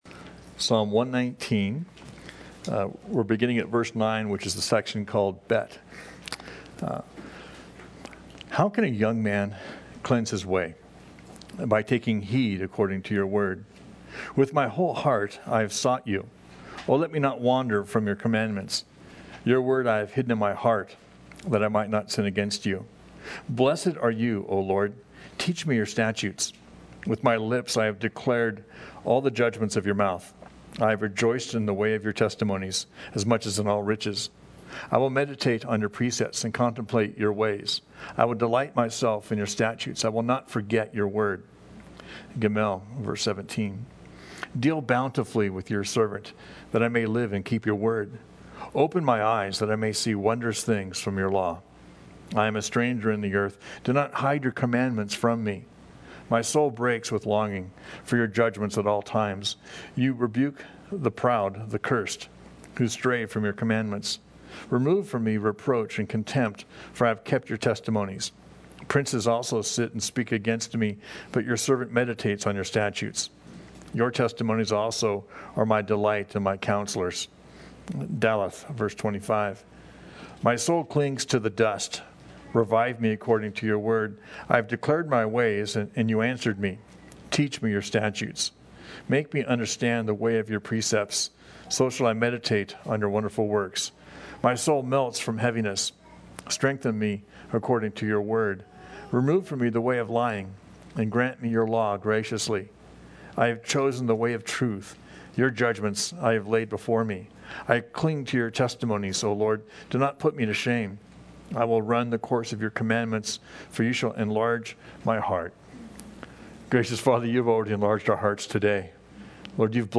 Praise & Worship Psalms 0 Comments Show Audio Player Save Audio Save PDF Your word I have hidden in my heart